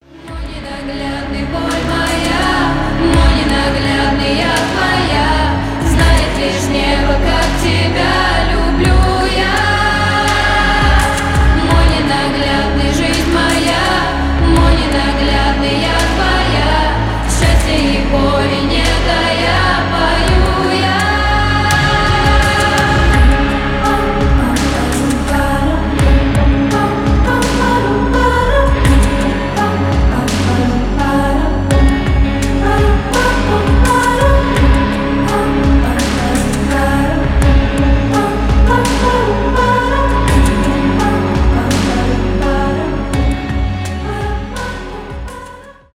поп
медленные , cover